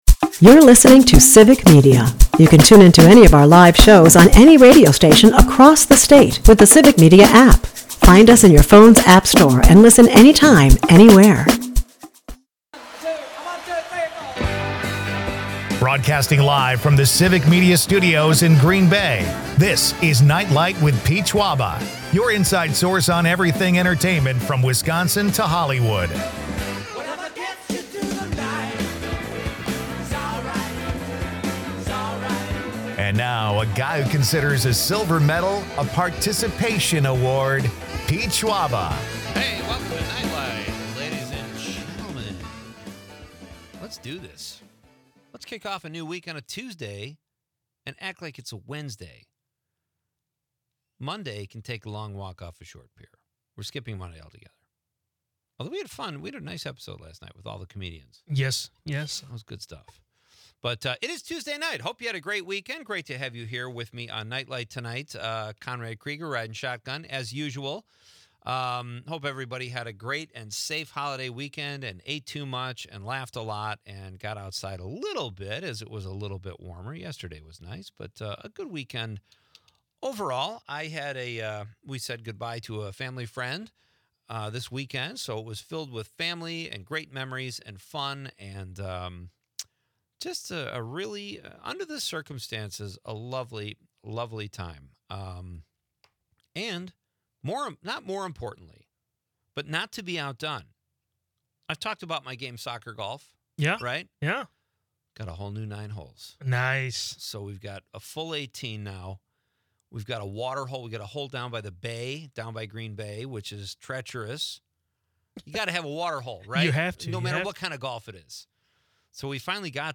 We also hear his Beach Boys-style song 'Driving with Grandpa.'